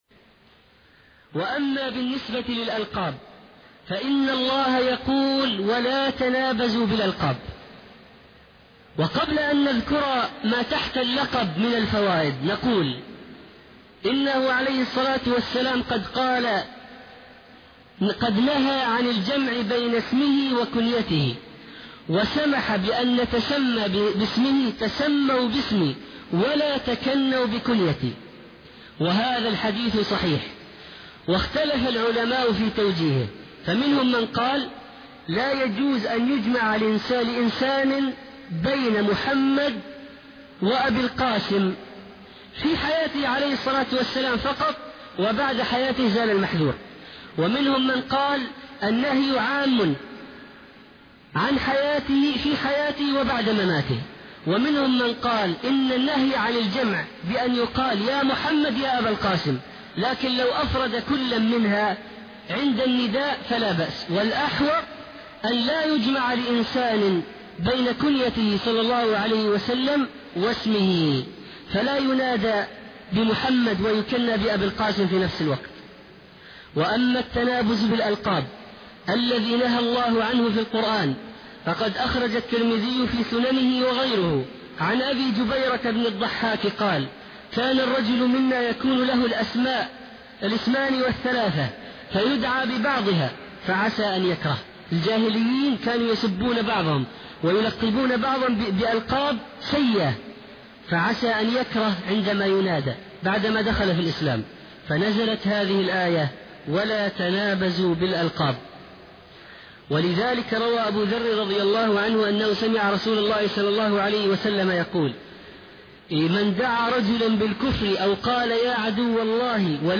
Отрывок из лекции